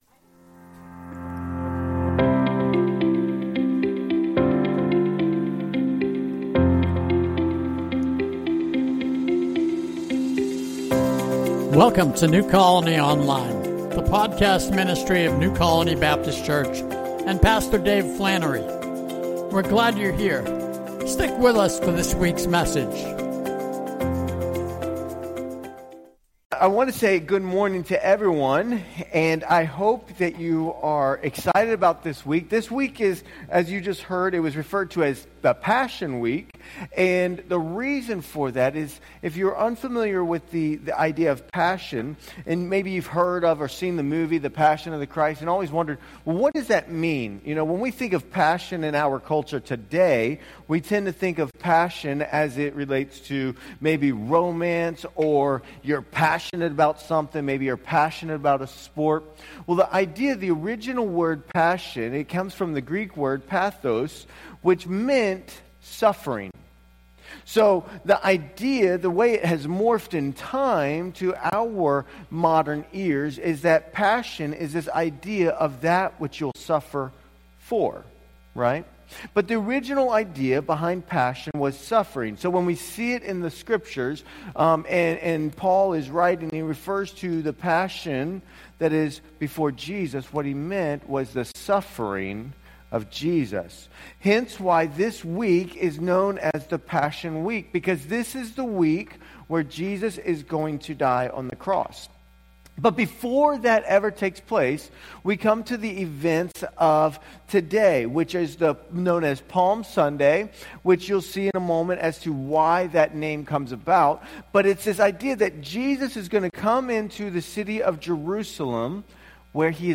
riverside_sermon_march29.mp3